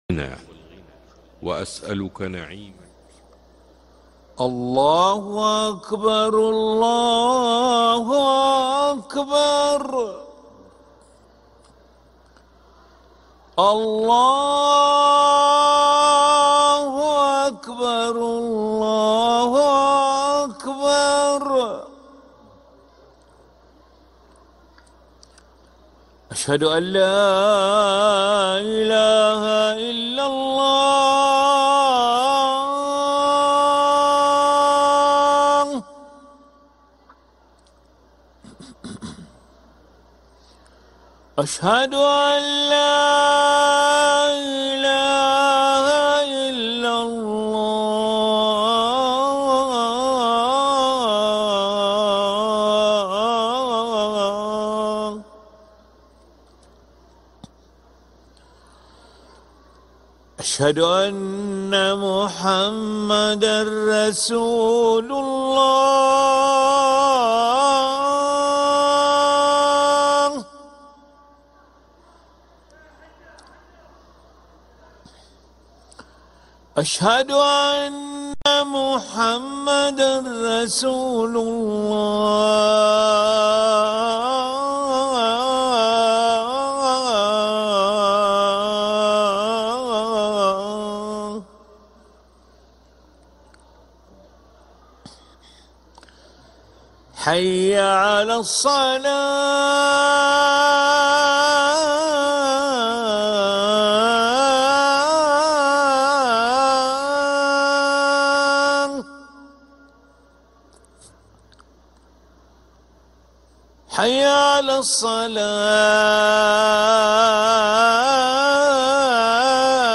أذان العشاء للمؤذن علي ملا الأحد 28 صفر 1446هـ > ١٤٤٦ 🕋 > ركن الأذان 🕋 > المزيد - تلاوات الحرمين